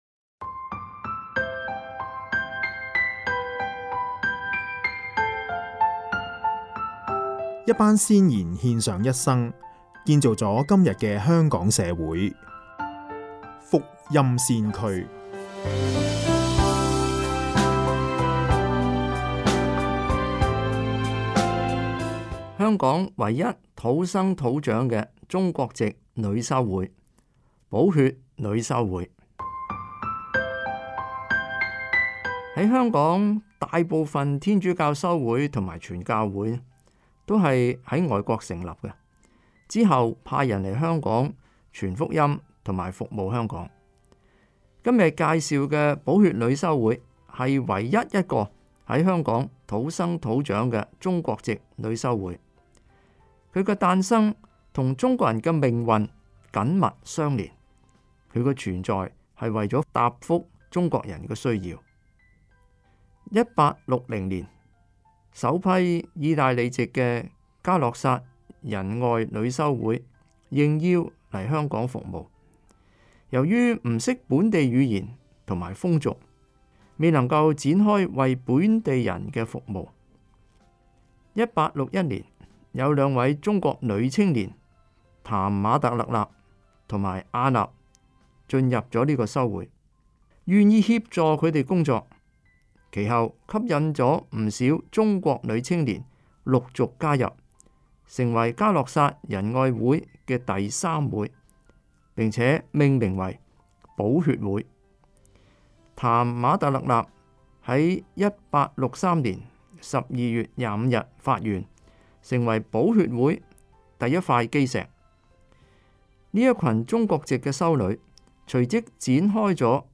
香港電台節目《豐富的人生》之《福音先驅》